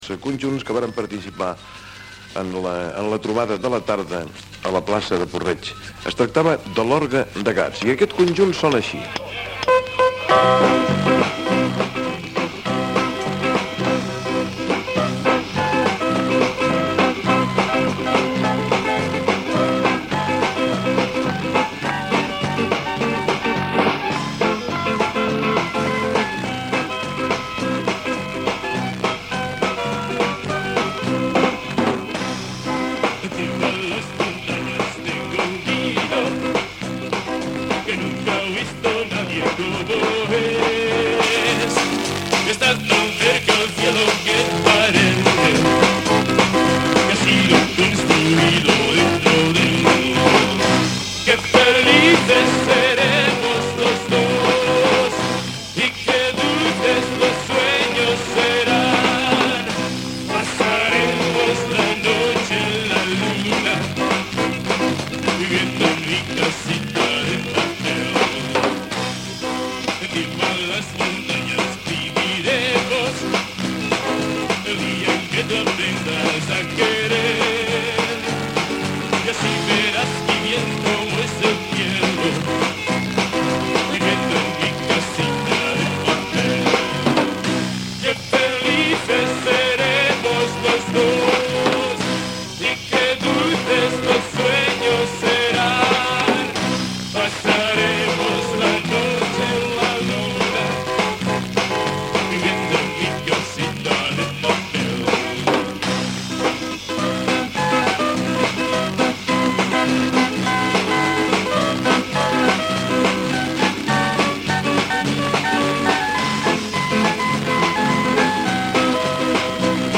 Esment a la trobada del programa que es va fer a Puig-reig. Cançó "La casita de papel" interpretada pel grup musical Orgue de Gats
Entreteniment